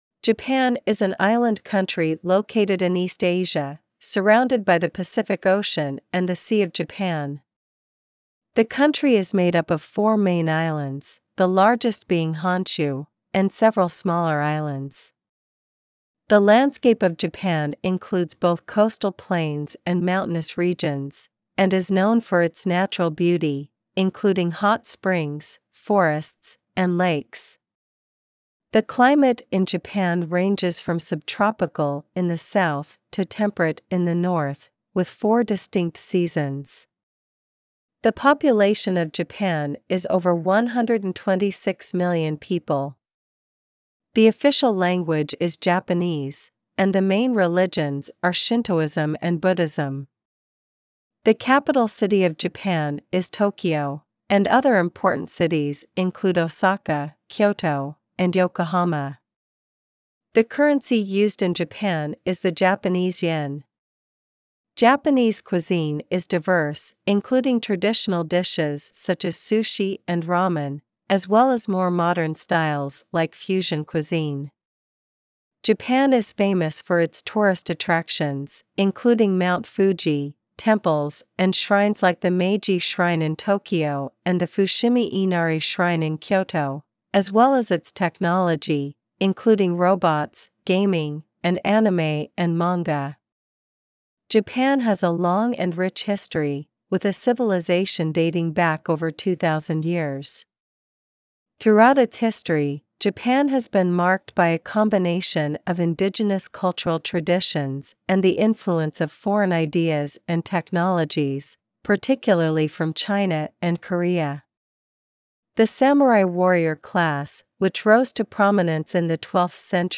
JAPAN: Reading
• a narration about the country
sample-japan-reading.mp3